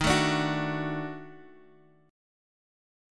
EbmM7b5 chord